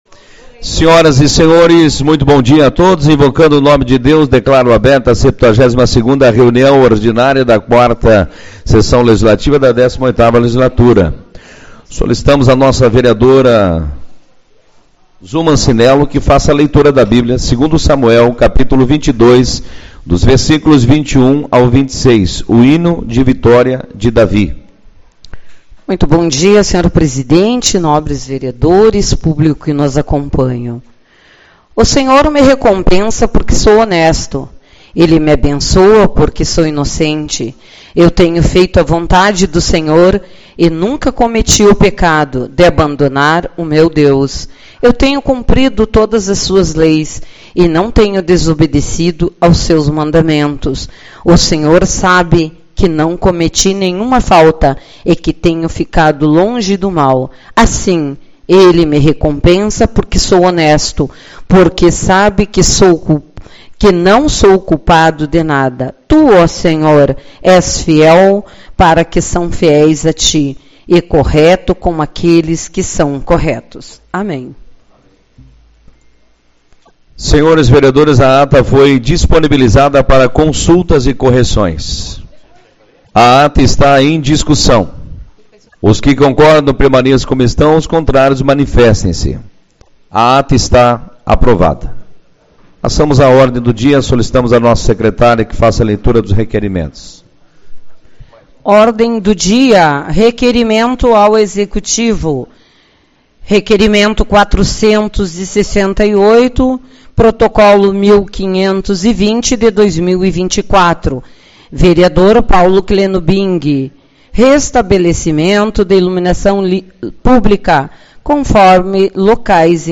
31/10 - Reunião Ordinária